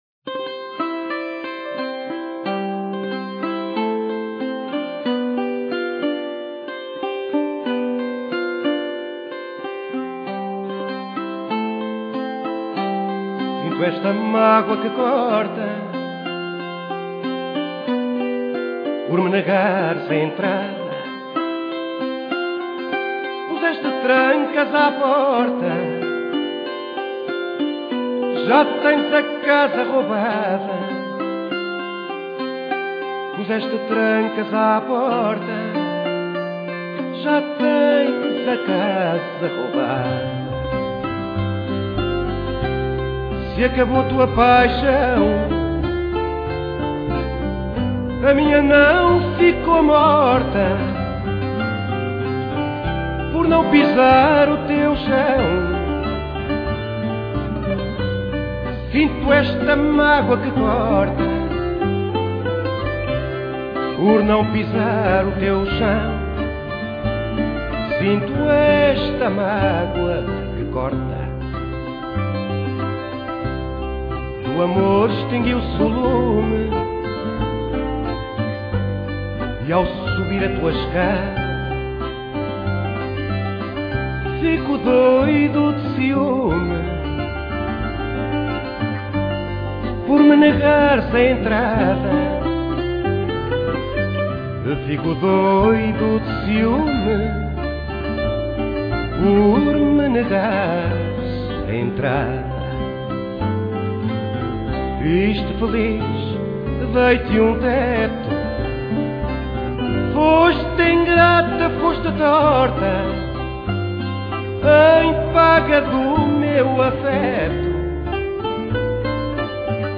Guitarra
Viola
ViolaBaixo